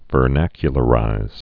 (vər-năkyə-lə-rīz)